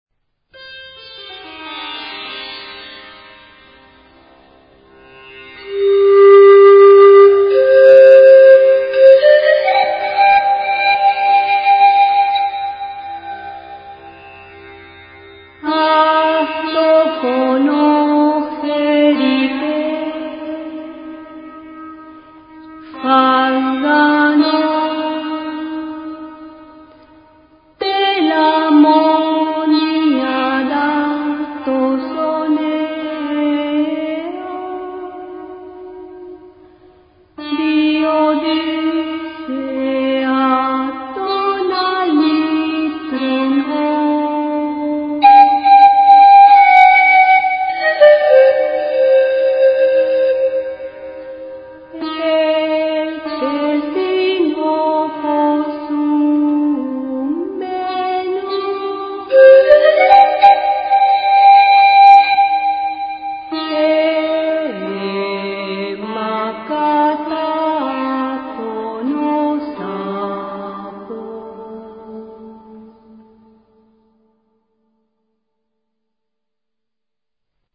Фрагмент античной песни